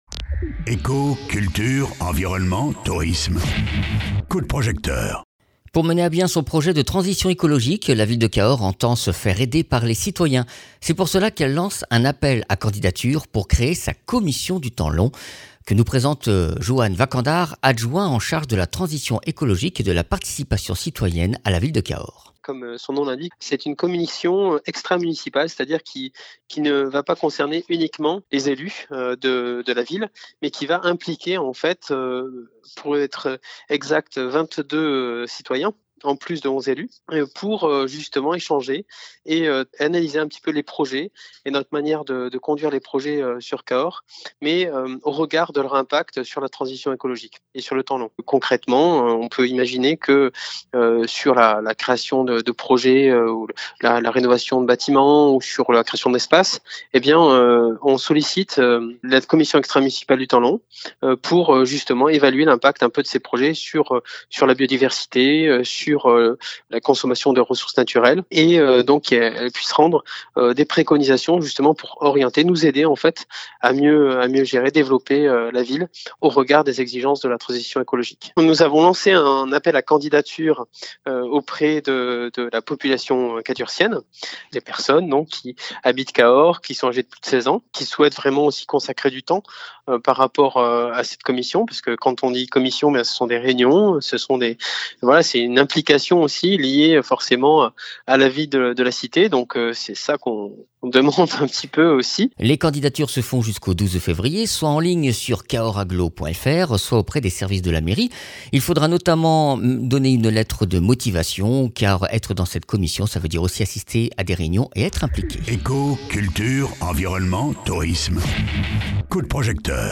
Interviews
Invité(s) : Johann Vacandare, adjoint au maire de Cahors